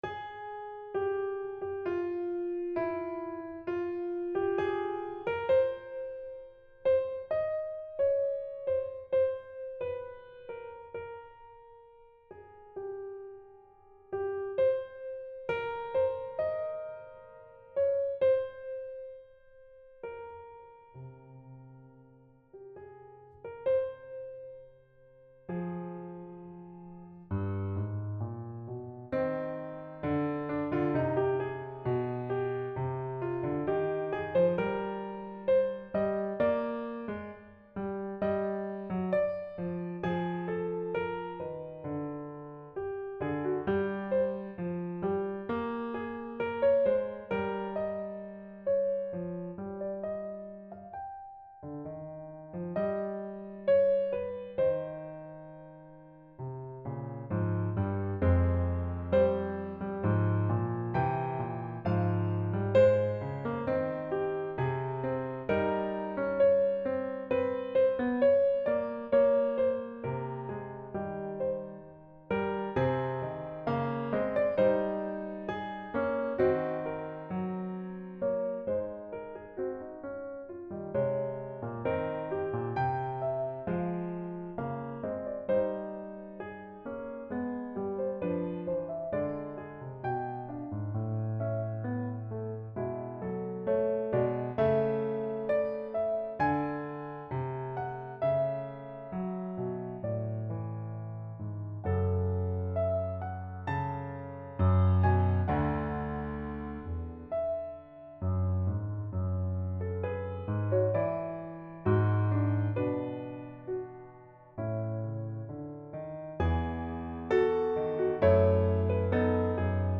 Little fugue on a theme by Bellini - Piano Music, Solo Keyboard
Including the weird modulation.